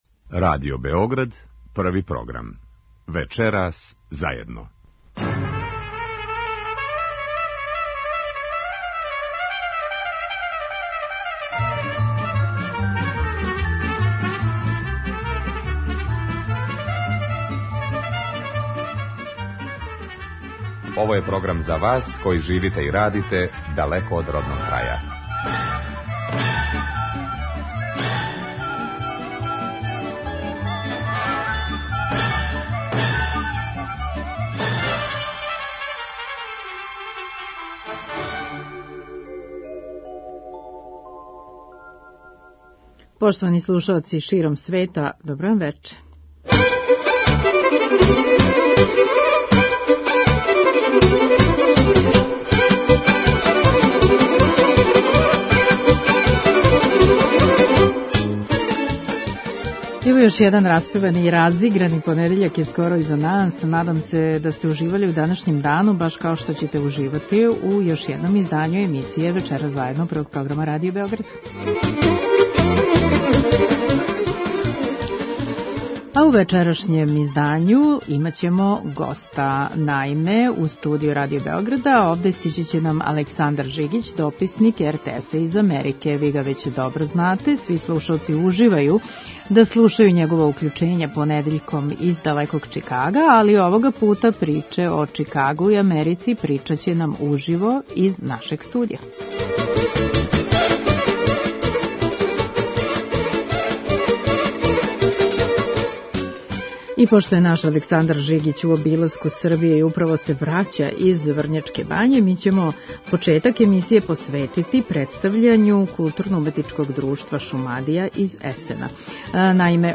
Овога пута приче о Чикагу и Америци причаће 'уживо', у студију Радио Београда.
Емисија магазинског типа која се емитује сваког петка од 21 час.